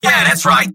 Robot-filtered lines from MvM. This is an audio clip from the game Team Fortress 2 .
{{AudioTF2}} Category:Scout Robot audio responses You cannot overwrite this file.